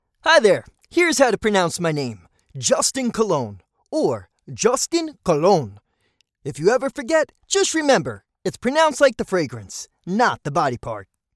NAME-PRONOUNCIATION.mp3